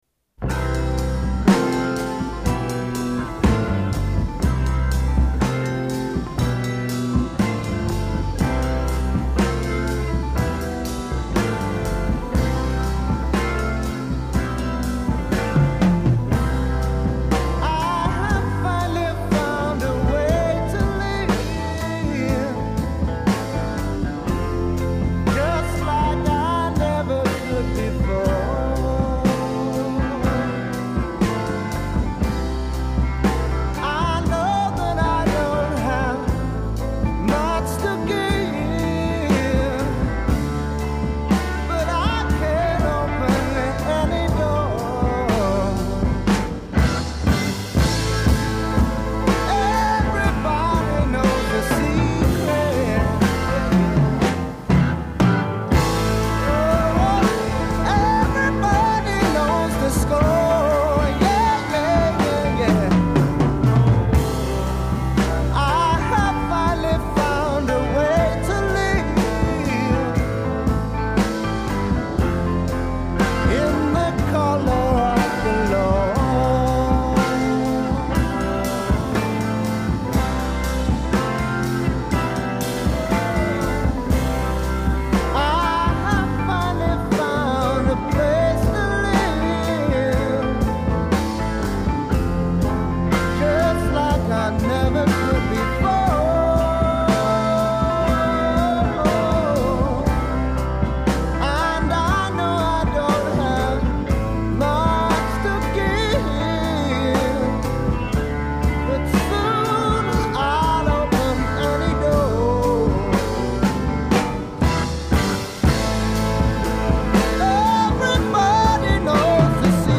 vocal and piano
bass guitar